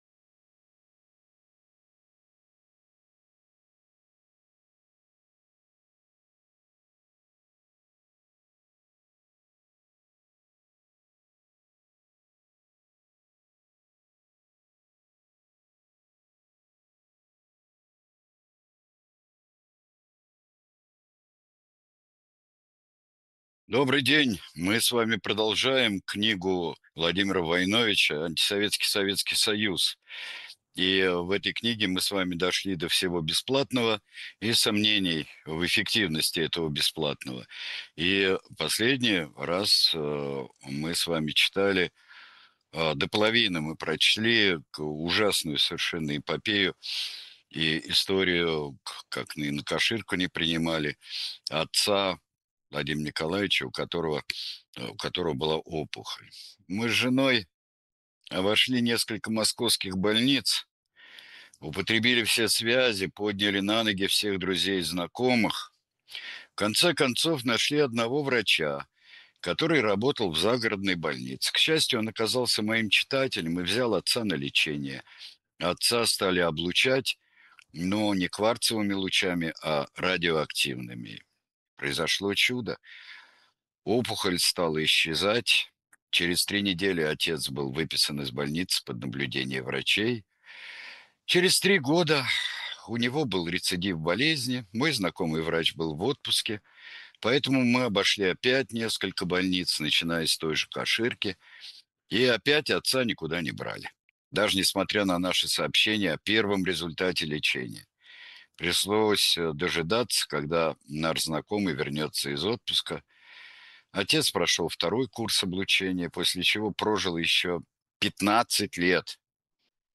Произведение читает Сергей Бунтман